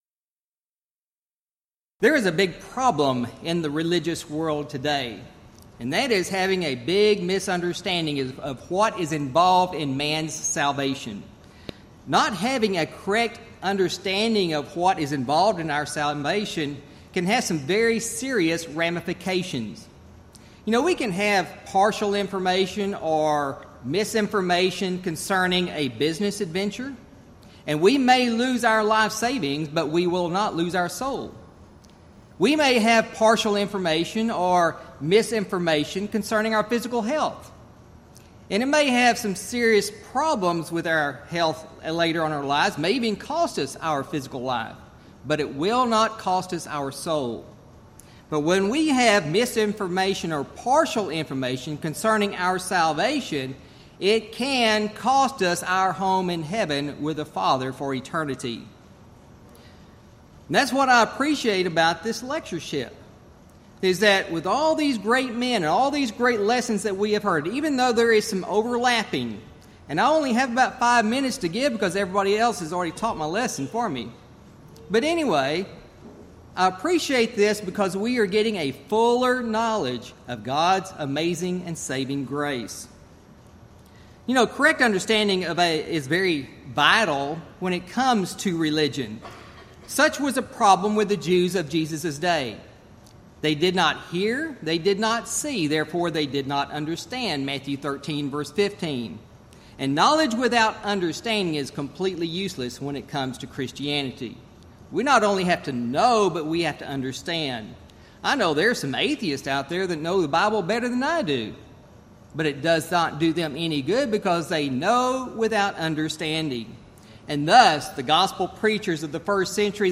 Event: 21st Annual Gulf Coast Lectures Theme/Title: The Amazing Grace of God
lecture